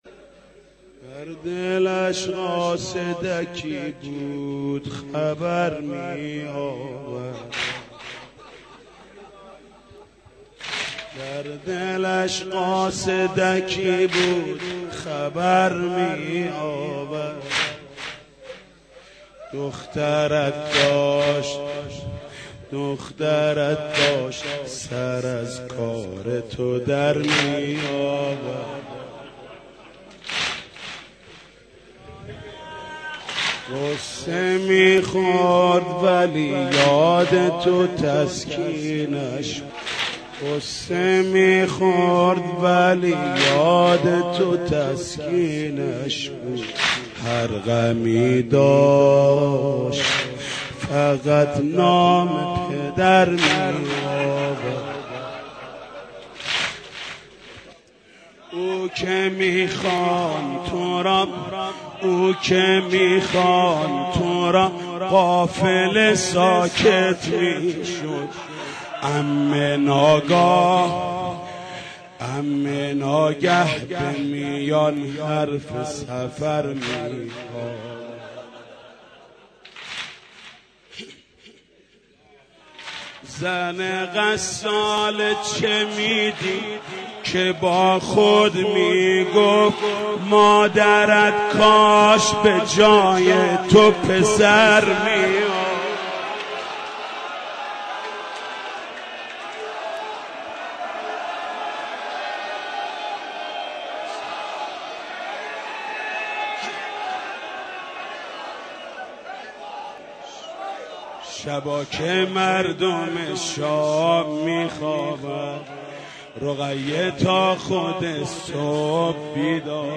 شعری از کاظم بهمنی با صدای محمود کریمی